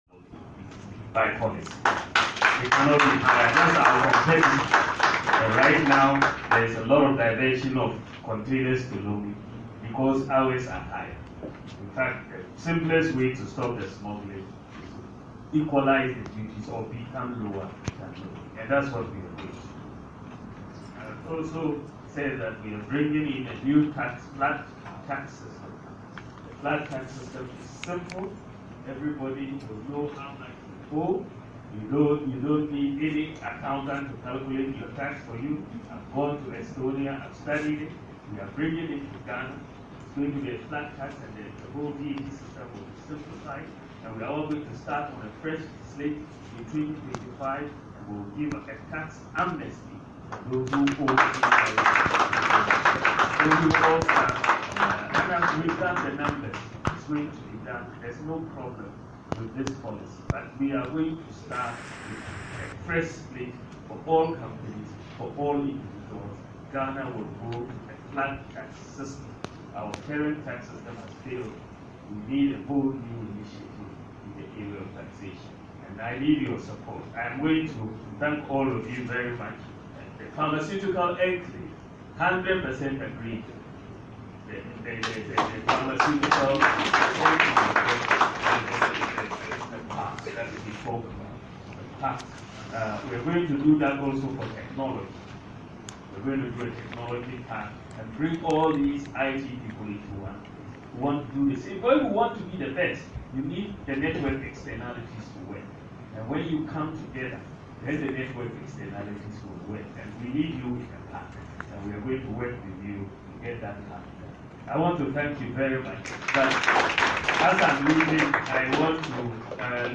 “Pharmacies and specialization is the number one skills needs. Let us get some more scholarships for the pharmacies and we will get it done,” Dr Mahamudu Bawumia said on Thursday, April 4 when he met members of the Pharmaceutical Society of Ghana, in Accra, to throw more light on his policies for the industry and the nation.